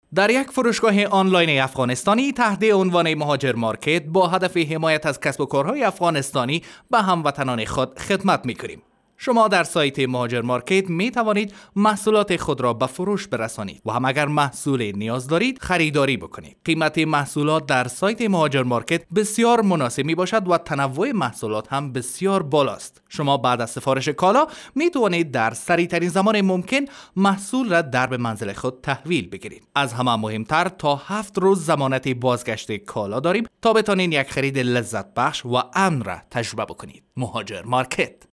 Male
Adult
Commercial